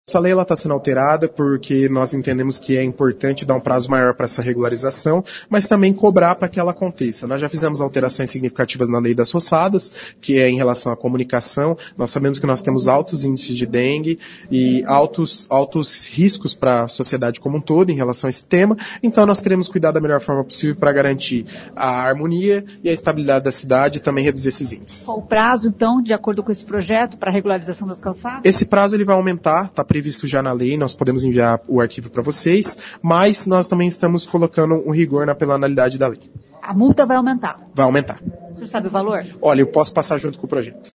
Ouça o que diz o líder do prefeito na Câmara, vereador Luiz Neto: